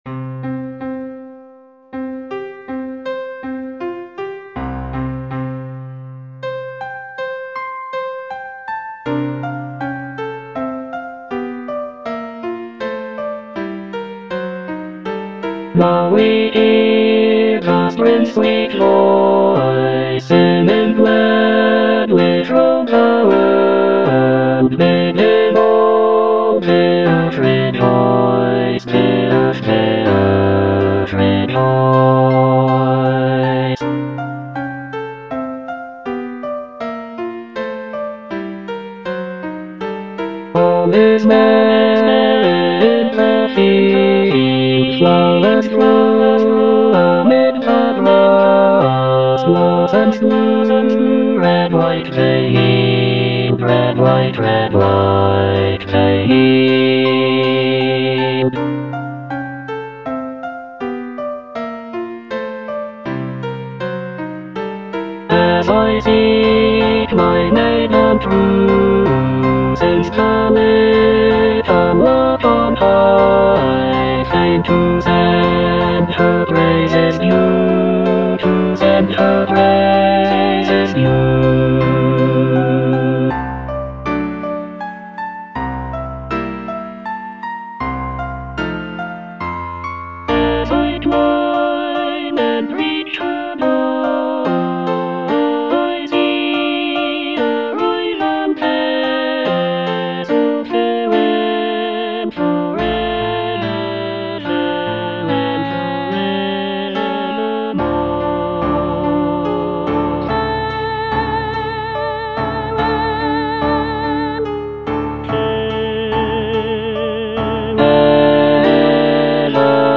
E. ELGAR - FROM THE BAVARIAN HIGHLANDS False love (bass I) (Emphasised voice and other voices) Ads stop: auto-stop
The piece consists of six choral songs, each inspired by Elgar's travels in the Bavarian region of Germany. The music captures the essence of the picturesque landscapes and folk traditions of the area, with lively melodies and lush harmonies.